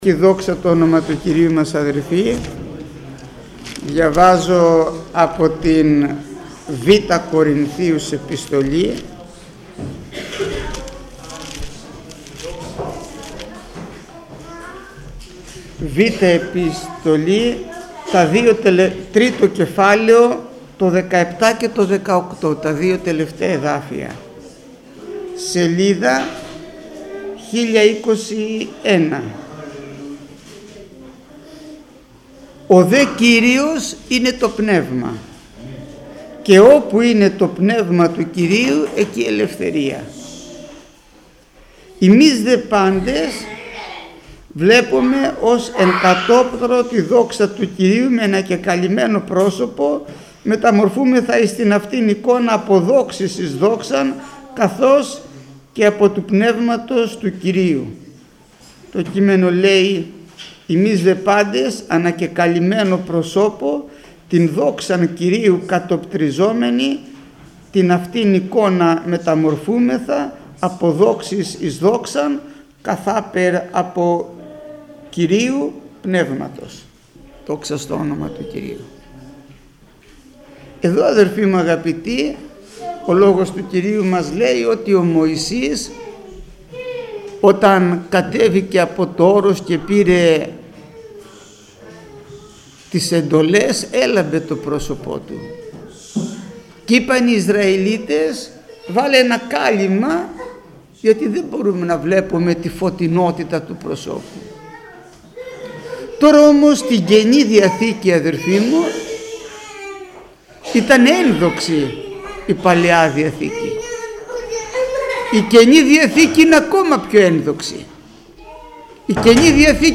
Μήνυμα πριν τη θεία κοινωνία
ΜΗΝΥΜΑΤΑ ΠΡΙΝ ΤΗ ΘΕΙΑ ΚΟΙΝΩΝΙΑ